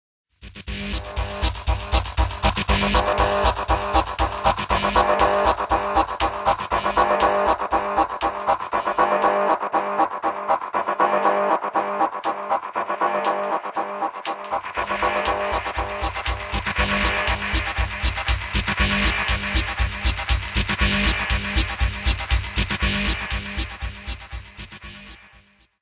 The Drummix in version 2 again uses the formerly invented beatmorph method to resample and retime stored samples and morph in between them thus making it possible to modify the complete sound seamlessly in real time.
Dynamic Relooped Mix
beatmorphremixpowerend.wma